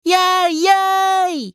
少年系ボイス～戦闘ボイス～